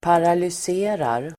Uttal: [paralys'e:rar]